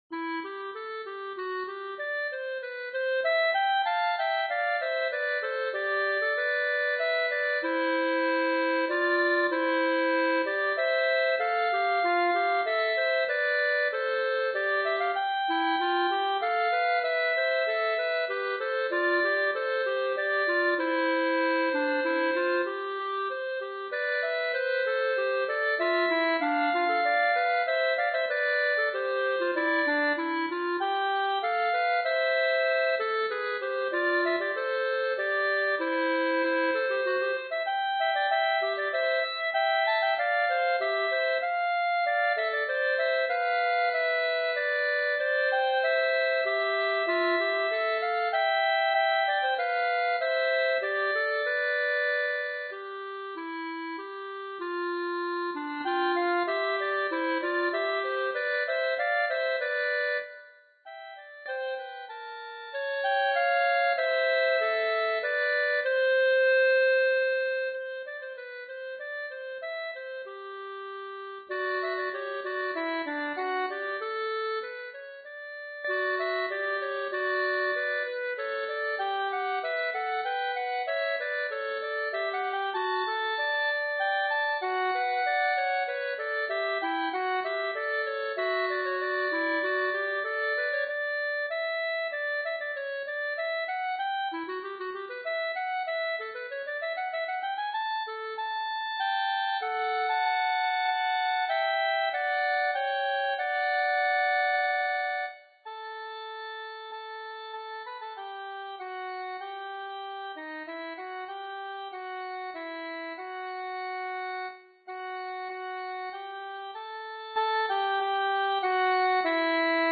Eb, F, bb
Duet: fl; cl; sax; ww
Song (binary)
(unaccompanied)